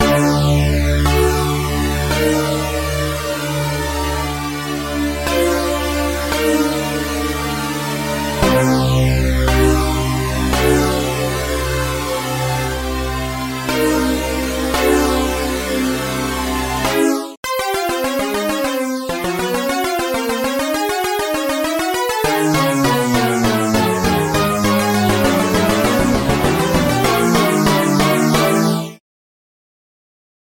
Bassesland KONTAKT is a virtual bass sample library in .nki format that emulates a wide range of sounds of electric, acoustic and synthesizer basses.
Oberheim OB-Xa Buzz Bass
Bassesland-32-Oberheim-OB-Xa-Buzz-Bass.mp3